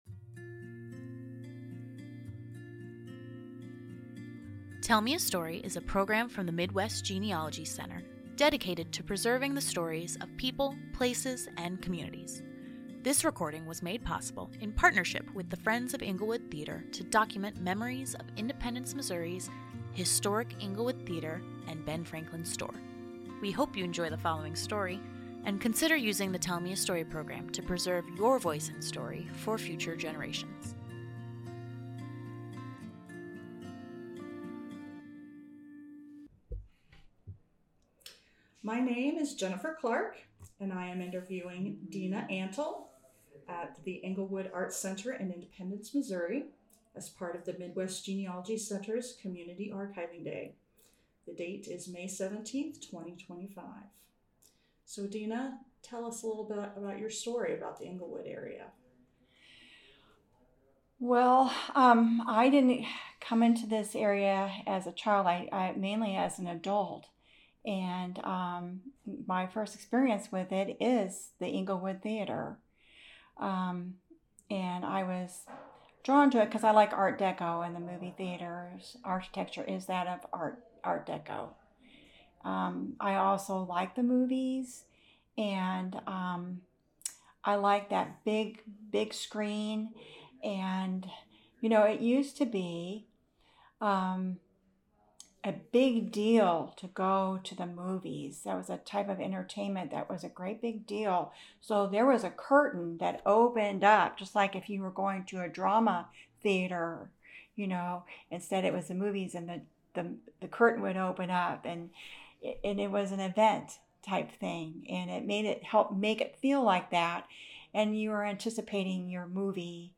Englewood Theater Community Archiving Day - Oral Histories
interviewer
interviewee